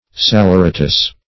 Salaeratus \Sal`ae*ra"tus\, n.